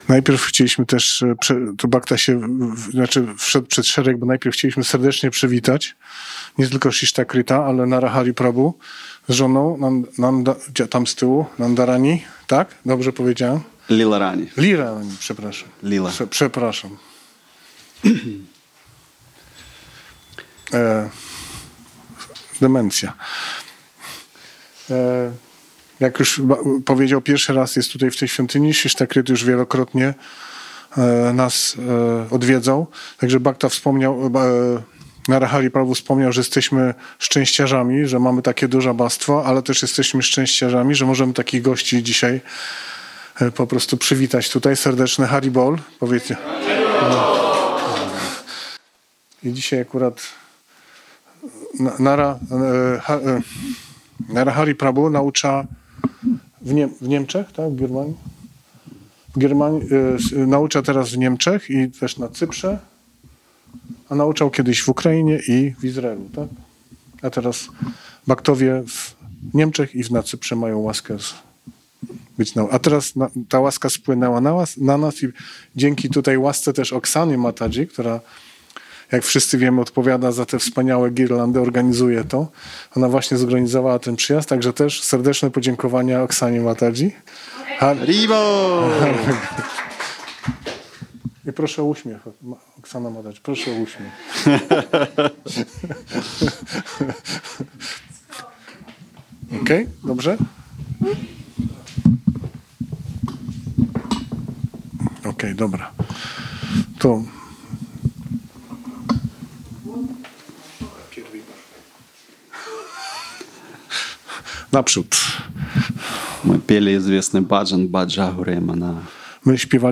Wykład jaki odbył się 24 sierpnia 2025 roku w języku rosyjskim z polskim tłumaczeniem.